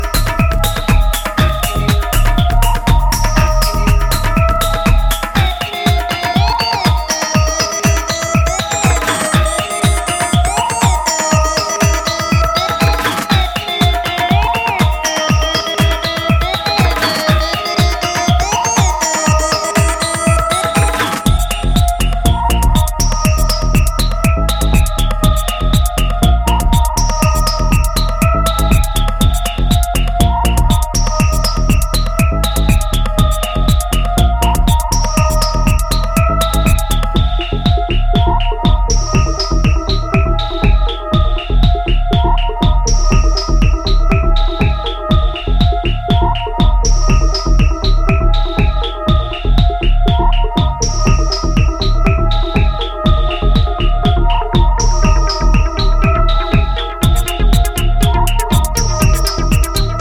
捩れまくるシンセのトーンに意識も遠のくサイケデリックなブリーピー・アシッド・ハウスで、これは中々に驚異的な傑作。
イントロ、中盤にぶち込まれるベースラインはDAFへのオマージュ？